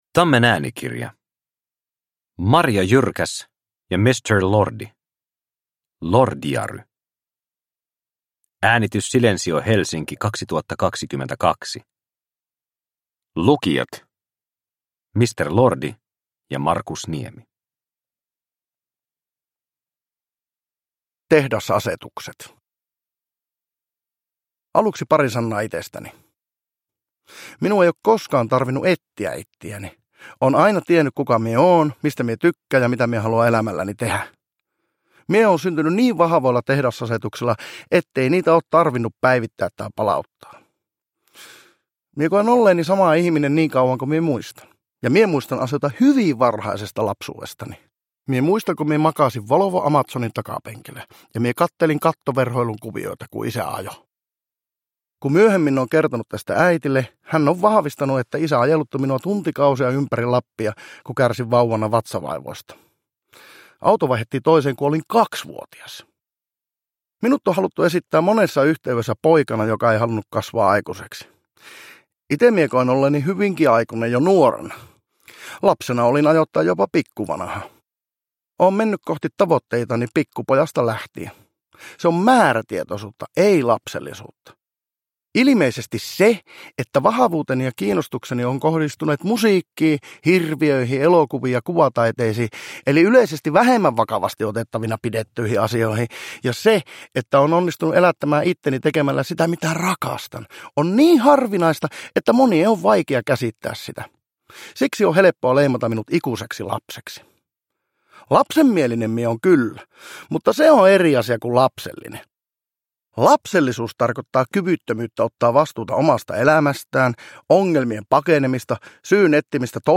Lordiary – Ljudbok – Laddas ner
Lordiary on väkevä, kertojansa kuuloinen tarina oman vision tinkimättömästä toteuttamisesta, taiteellisen vapauden puolustamisesta ja pikkutarkasta uurastuksesta.